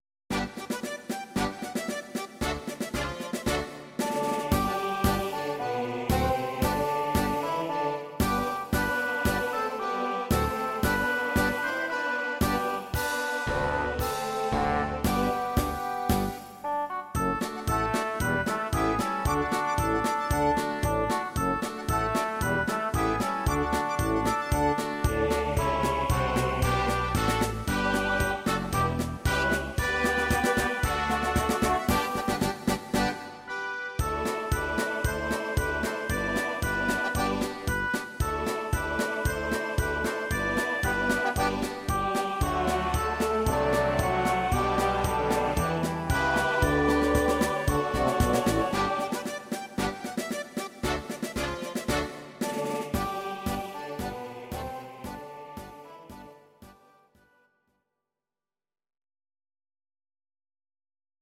These are MP3 versions of our MIDI file catalogue.